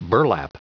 Prononciation du mot burlap en anglais (fichier audio)
Prononciation du mot : burlap